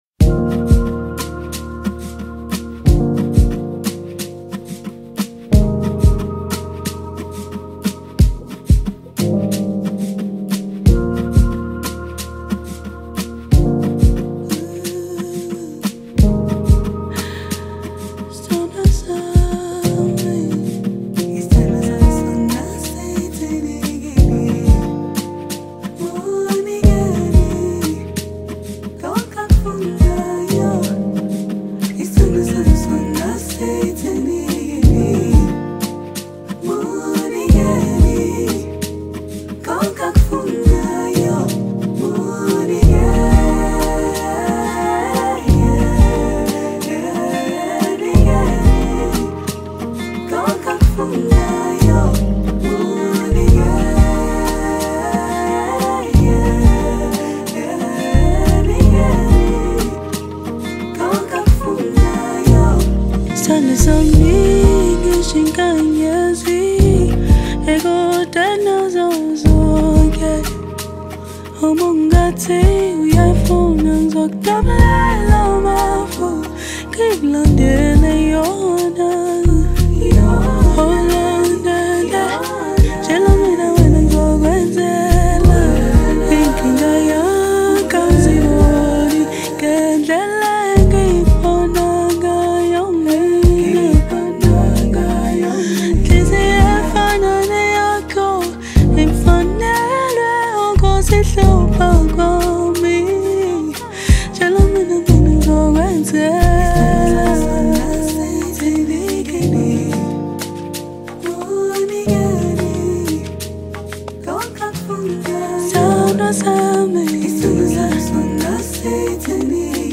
Home » Amapiano